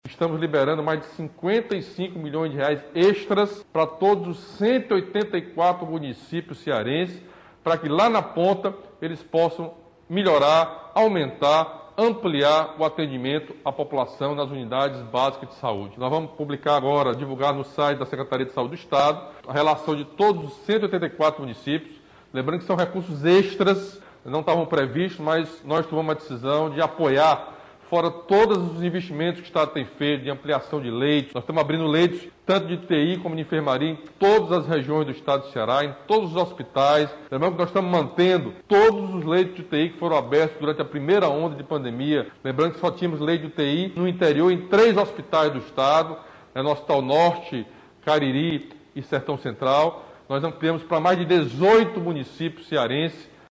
O anúncio foi feito na manhã desta quinta-feira (20) pelo governador Camilo Santana.